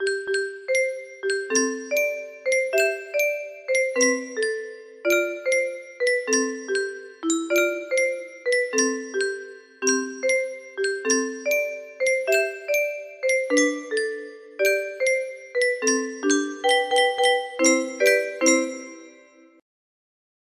30230 music box melody